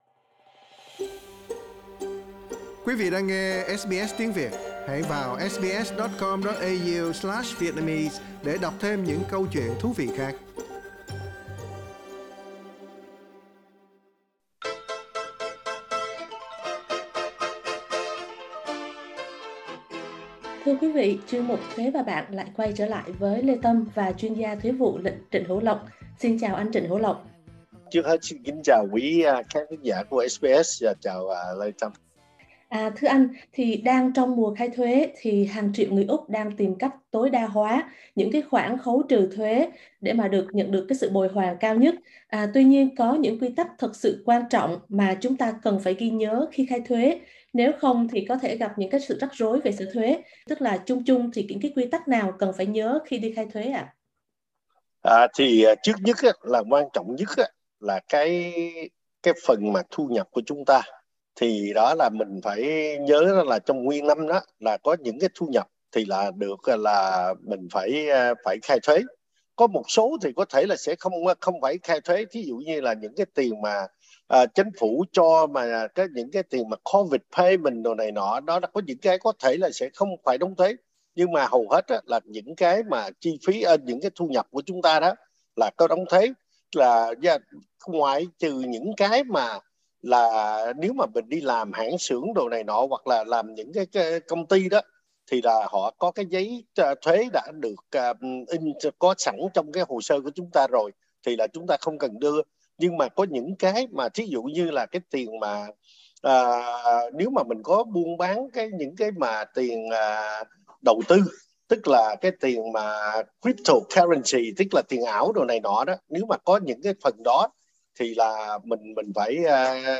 Mời quý vị bấm vào biểu tượng radio để nghe toàn bộ bài phỏng vấn với chuyên gia thuế vụ